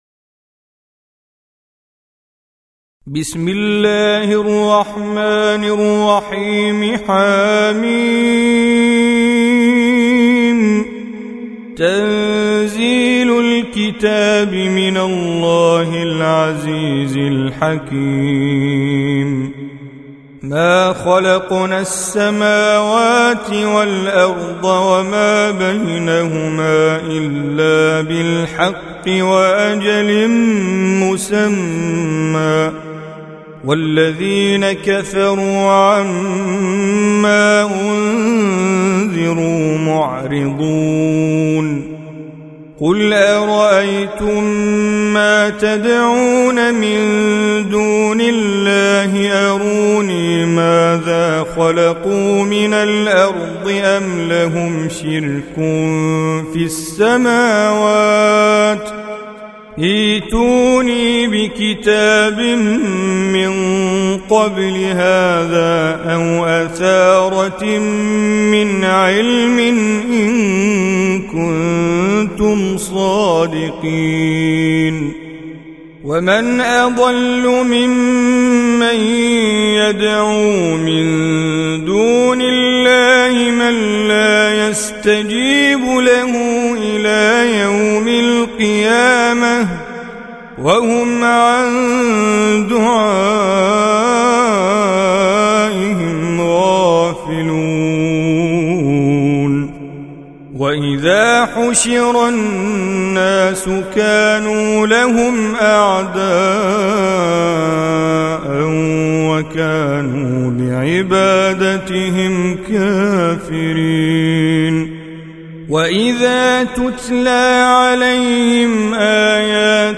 Audio Quran Tajweed Recitation
Surah Sequence تتابع السورة Download Surah حمّل السورة Reciting Mujawwadah Audio for 46. Surah Al-Ahq�f سورة الأحقاف N.B *Surah Includes Al-Basmalah Reciters Sequents تتابع التلاوات Reciters Repeats تكرار التلاوات